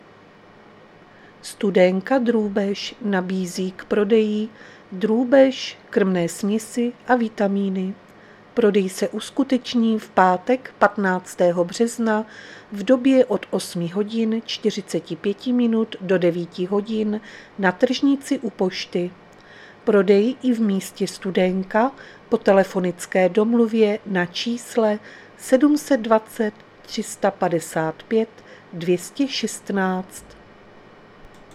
Záznam hlášení místního rozhlasu 14.3.2024
Zařazení: Rozhlas